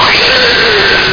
creatdie.mp3